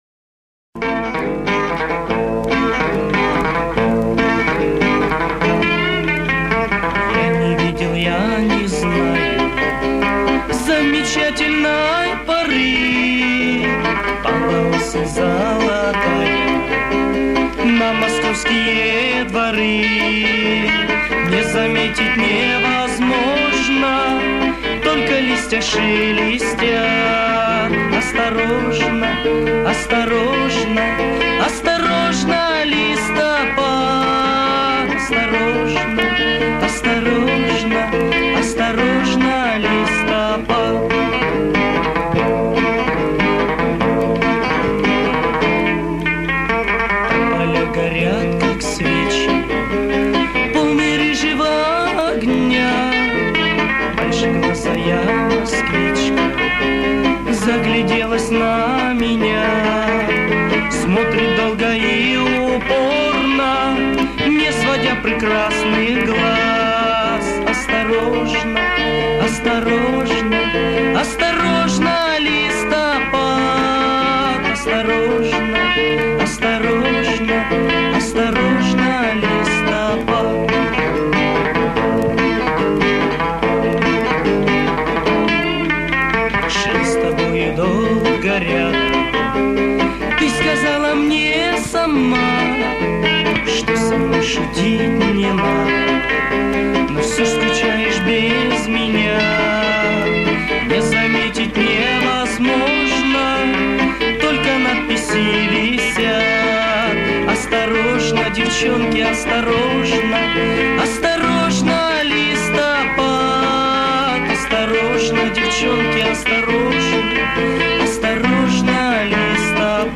Качество плохое и исполнителя не знаю. а так вроде та...